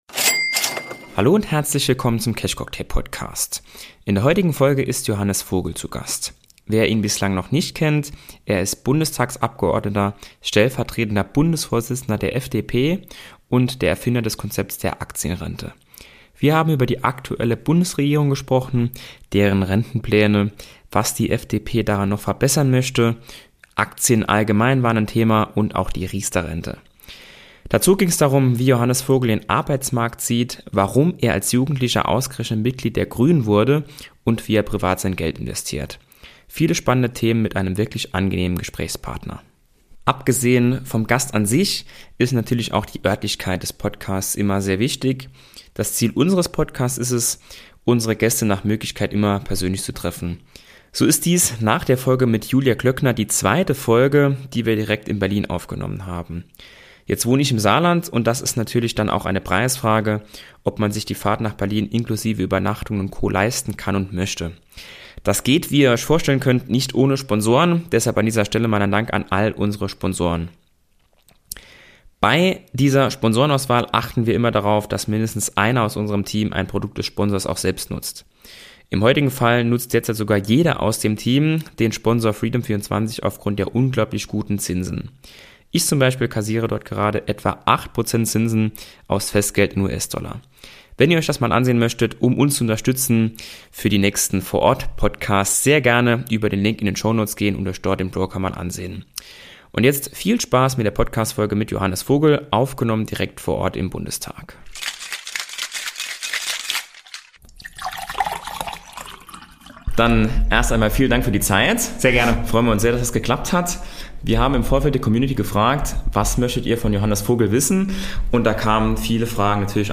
Der stellvertretende FDP-Bundesvorsitzende und Erfinder der "Aktienrente" stand Rede und Antwort.